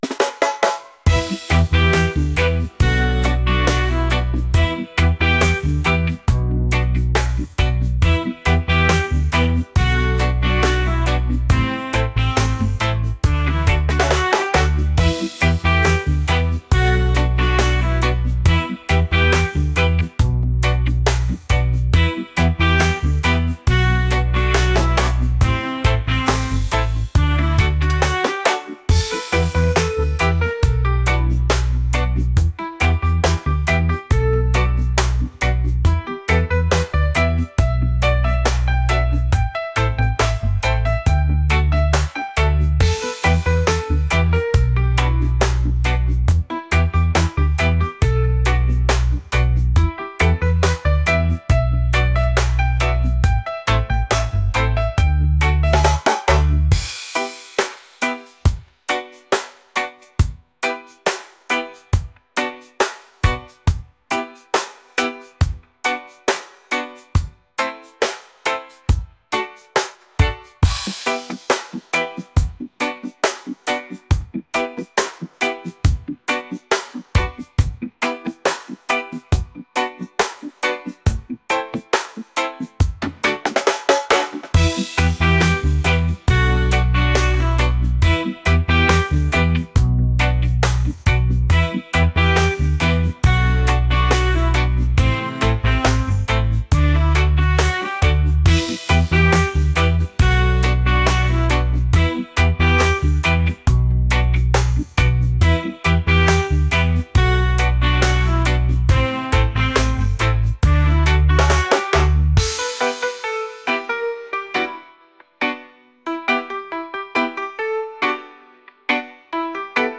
groovy | reggae | laid-back